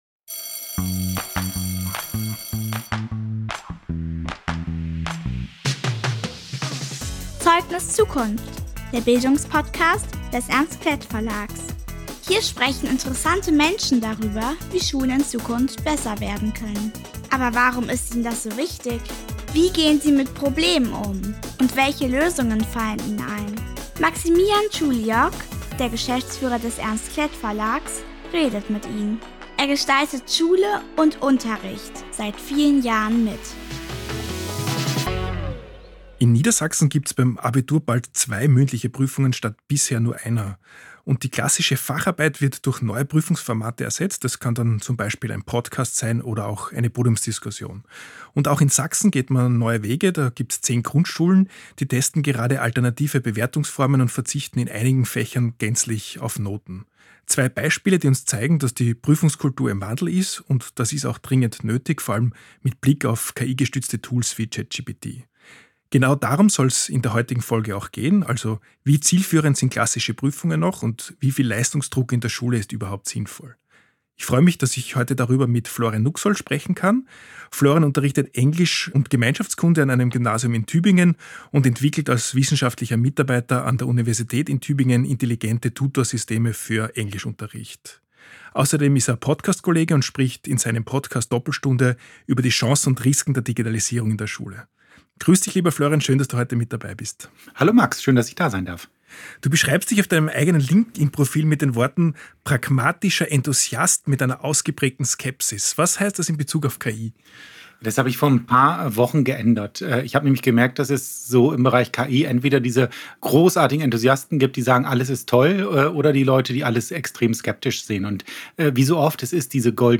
KI und Schule: Warum wir Prüfungen neu denken müssen ~ Zeugnis:Zukunft – Der Bildungspodcast des Ernst Klett Verlags Podcast